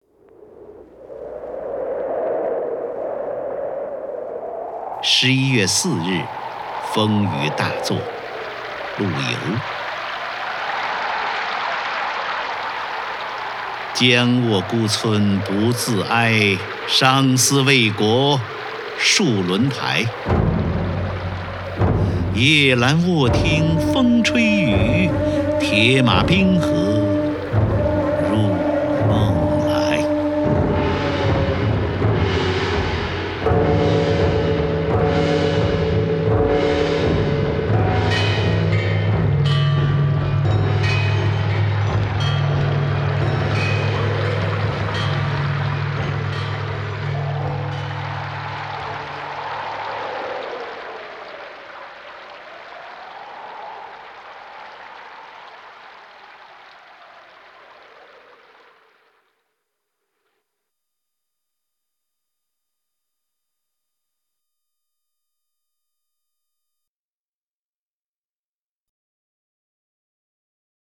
张家声朗诵：《十一月四日风雨大作》(（南宋）陆游)　/ （南宋）陆游
名家朗诵欣赏 张家声 目录